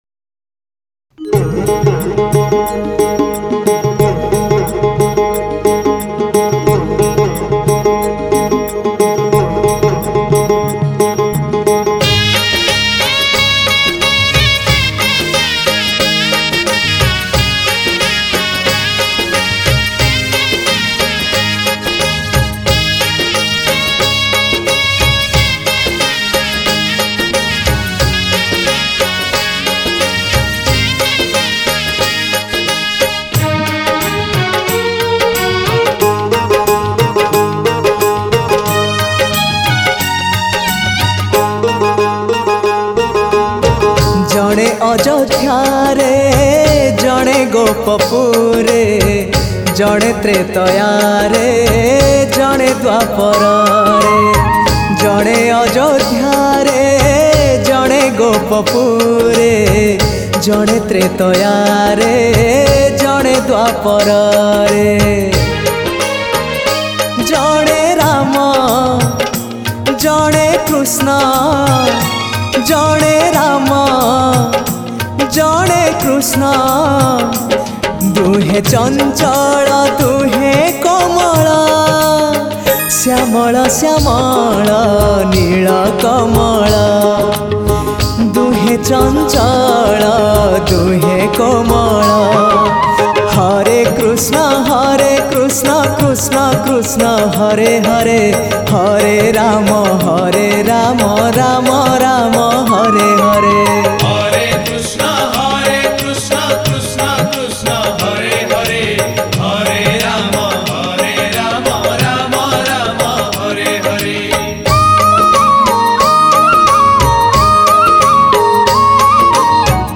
Odia Bhajan Song
Odia Bhajan Songs